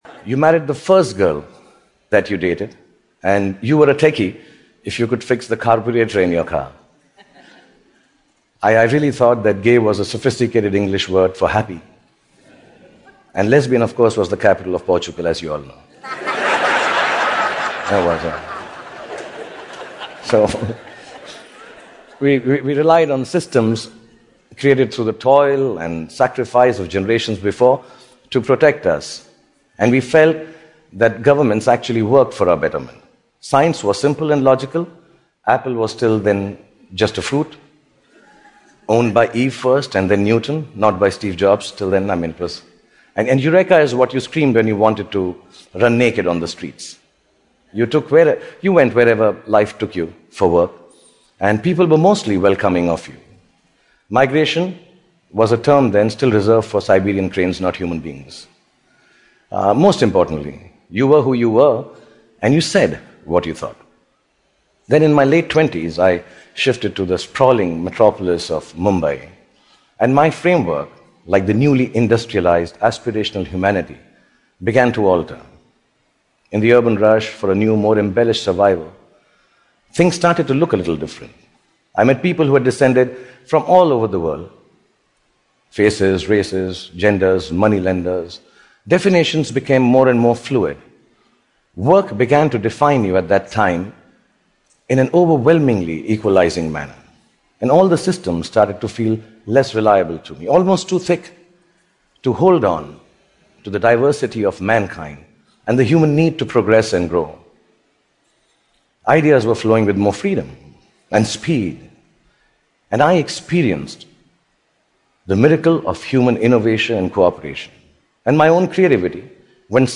TED演讲：关于人性名利和爱(3) 听力文件下载—在线英语听力室